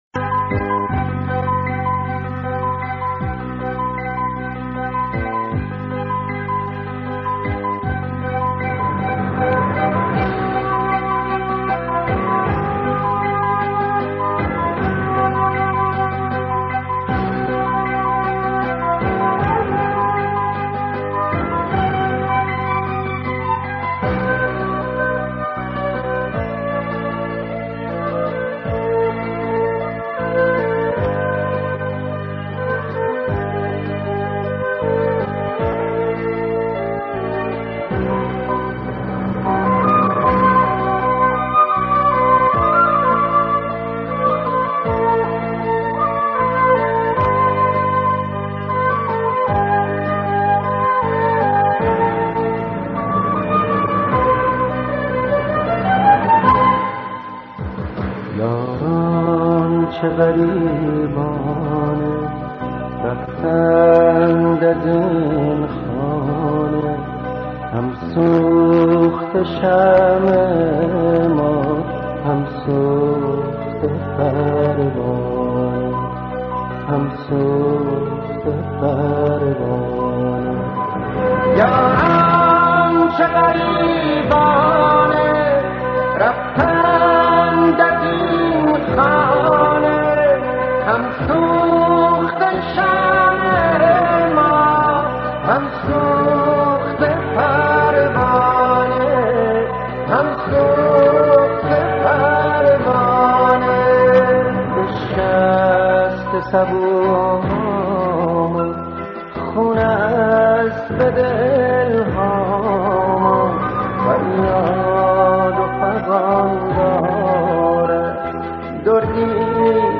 درخاطرم شد زنده یاد فاطمیون یاد شلمچه یاد مجنون/شهید محمدابراهیم همت که بود؟+مداحی